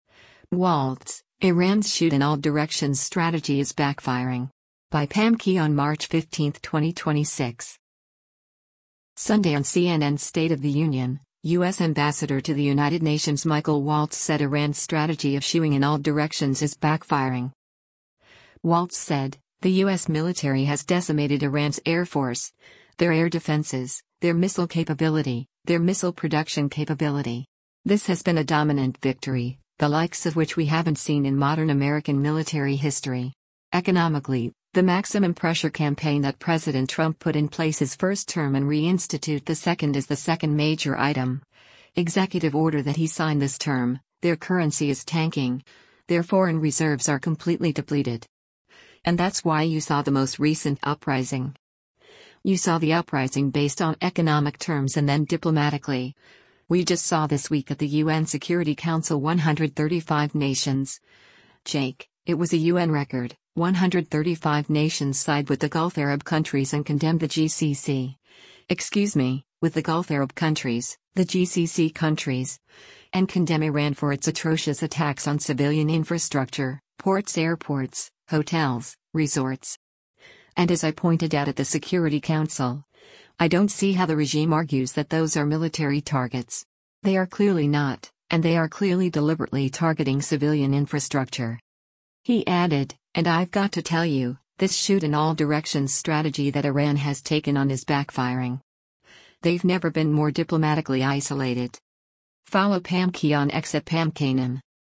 Sunday on CNN’s “State of the Union,” U.S. Ambassador to the United Nations Michael Waltz said Iran’s strategy of shooing in all directions is “backfiring.”